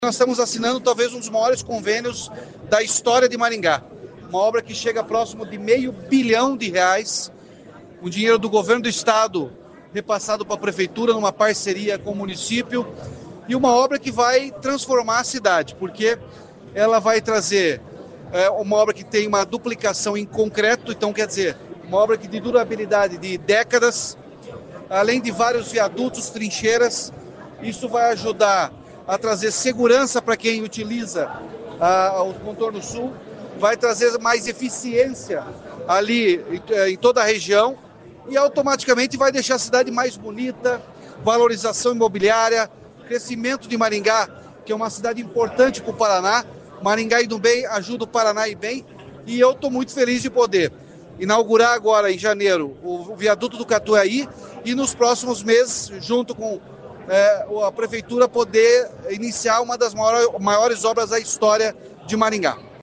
Nessa quarta-feira (10) o governador Ratinho Junior veio a Maringá para a assinatura do convênio com a Prefeitura de Maringá.